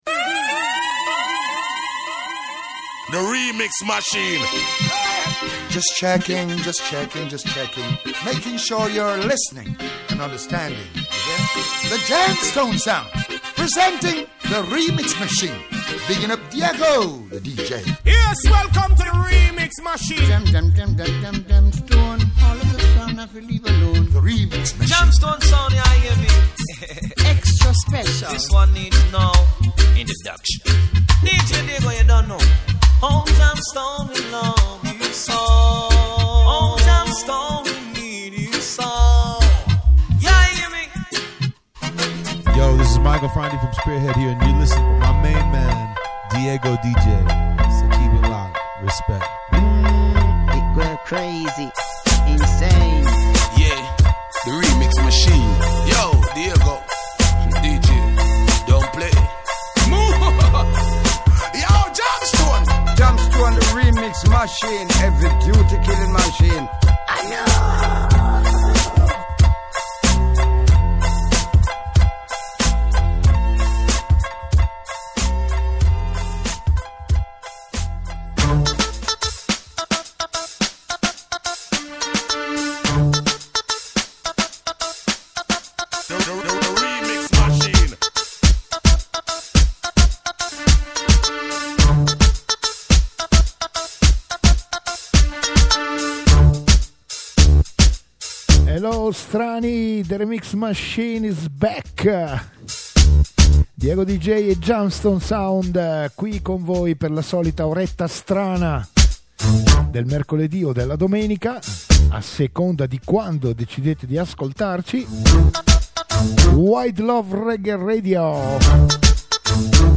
Reggae Remix